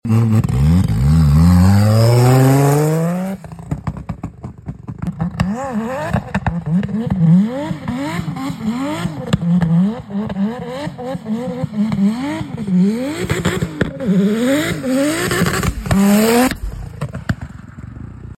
Mercedes M104 teszt AMTS előtt sound effects free download